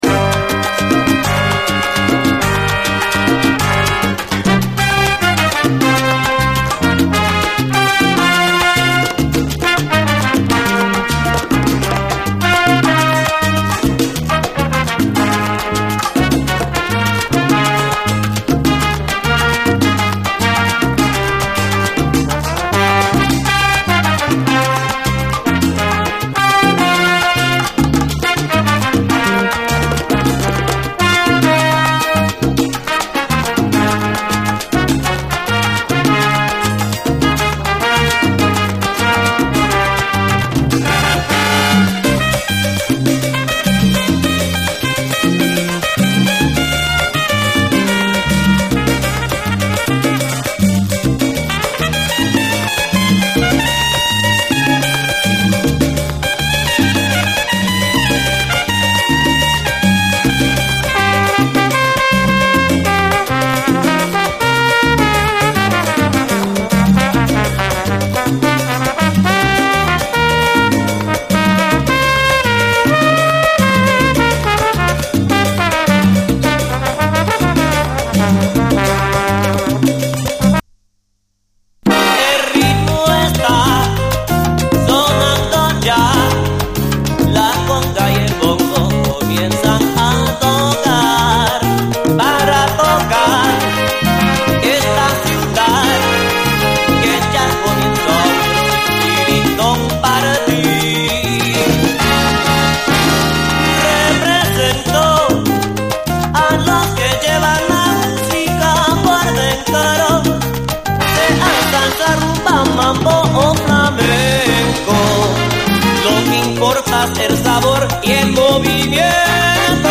ワシントンD.C.のGO-GOシーンを代表する名盤！
トロピカルなブレイキン・ファンク
コク深くゴージャスなムードがタマラナイ。